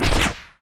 etfx_shoot_rocket02.wav